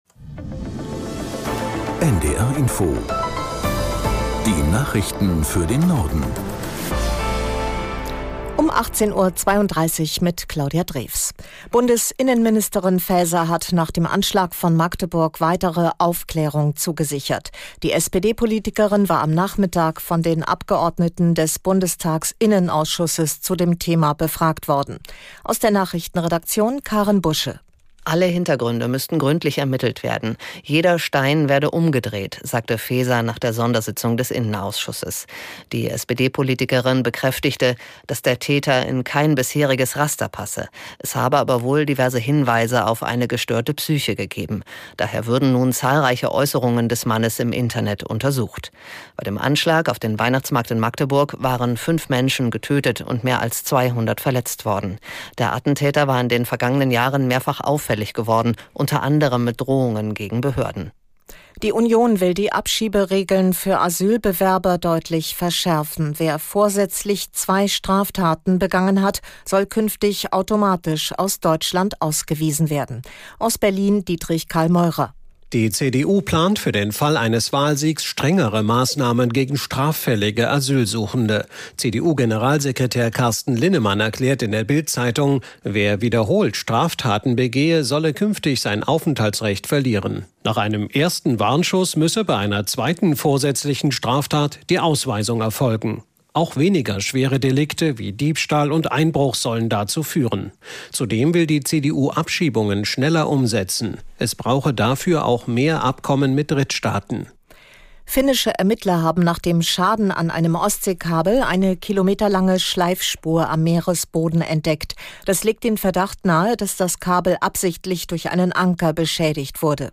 NDR Info - Nachrichten